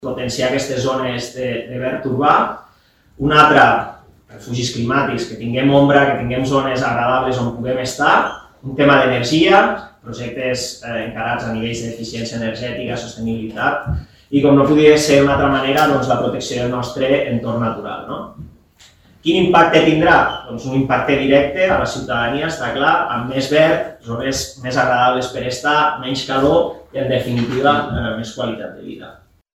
El passeig del riu de Santa Coloma és un altre projecte que es vol intervenir. Com que s’està desenvolupant des del punt de vista comercial, es vol adaptar la zona d’una manera més “agradable”, ha puntualitzat el conseller de Serveis Públics, Medi Ambient i Patrimoni Natural, Jordi Cabanes.